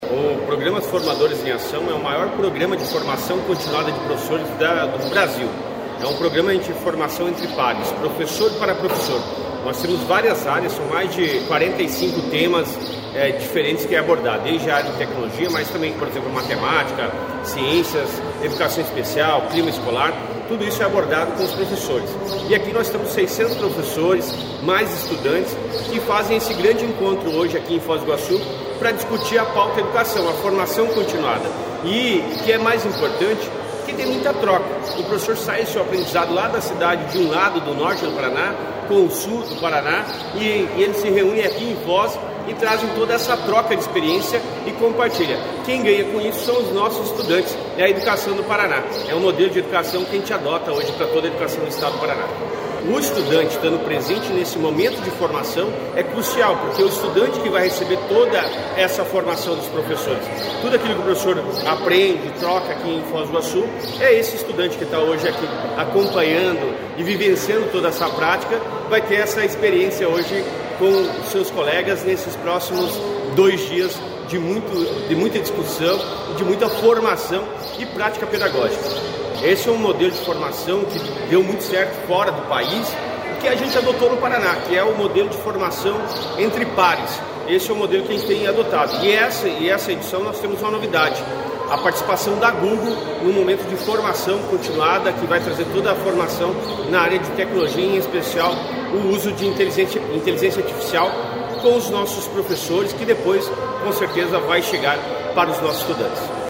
Sonora do secretário Estadual da Educação, Roni Miranda, sobre o congresso de formação continuada que reúne 800 professores em Foz